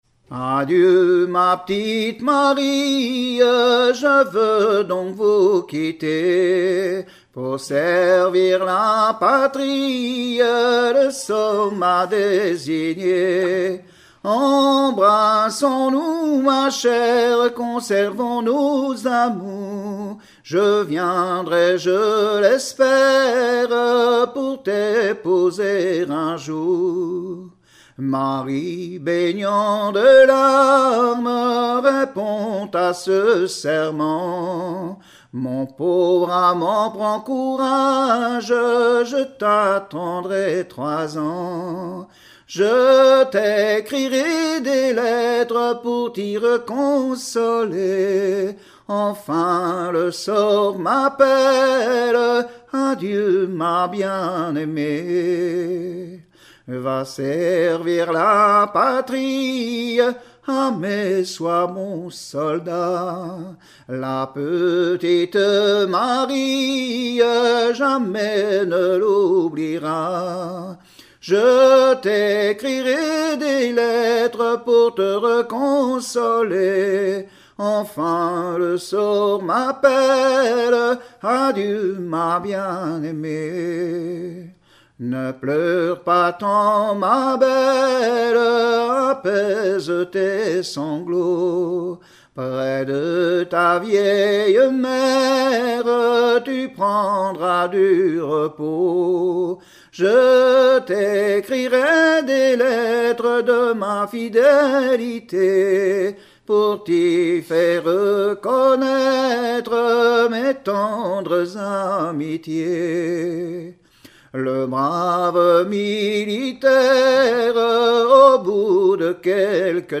Saint-Jean-de-Monts
Genre strophique
Pièce musicale inédite